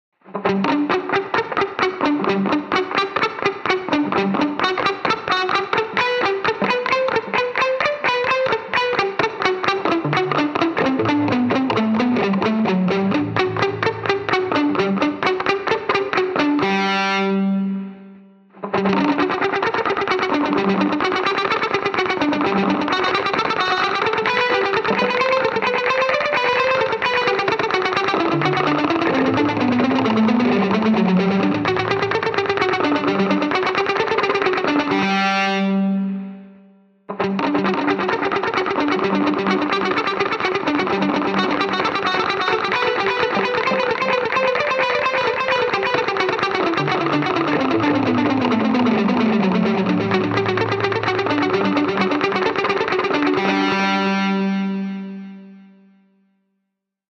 Je pars d'un petit truc joué en croches à 130 BPM.
ICI, tu as les exemples avec, à la suite, la mélodie "nue" puis avec le delay à la double-croche, puis le Delay à la croche pointée.
Et enfin l'astuce du "maître" : c'est encore plus efficace avec des motifs brisés (voir fichier).